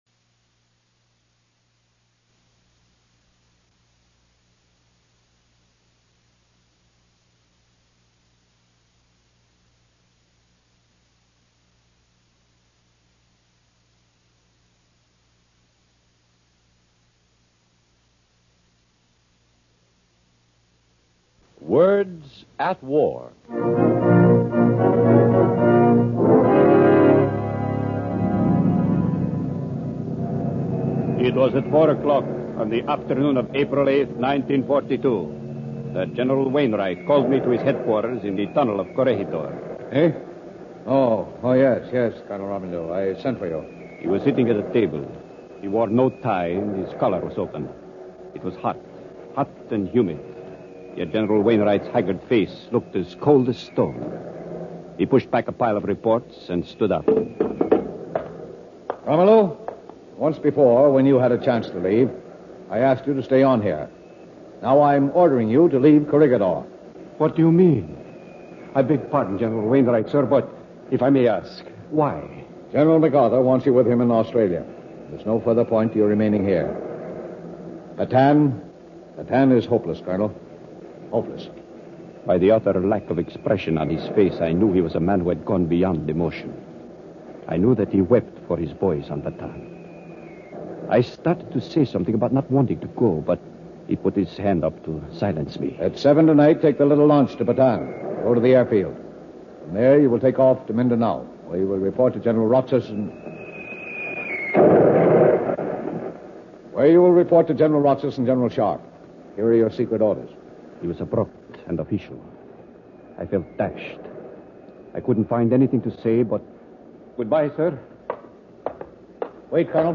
Words At War, the series that brings you radio versions of the leading war book another adaptation of an important war book, “Mother America” by Colonel Carlos Piromilo.